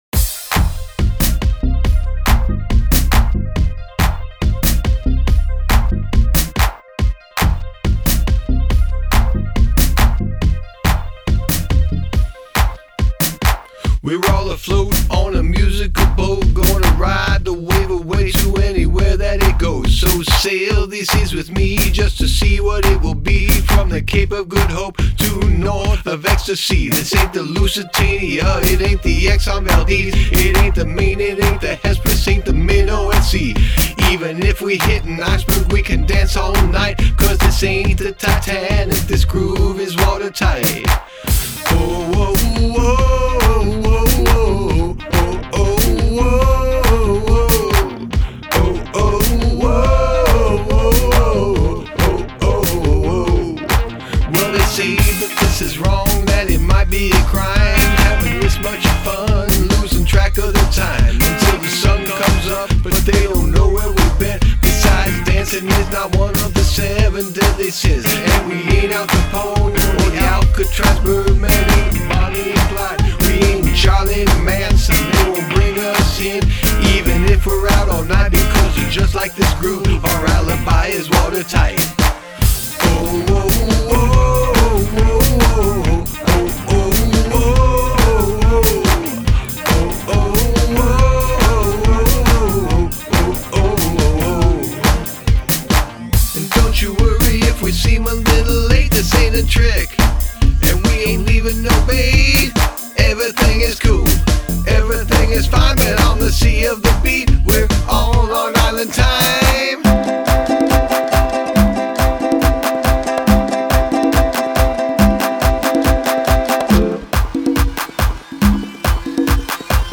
Ukelele
Additional crowd vocals on chorus